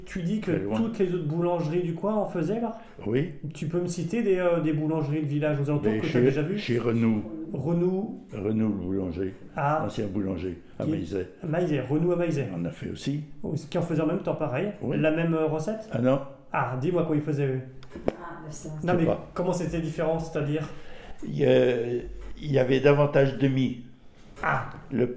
Enquête autour du préfou
Témoignage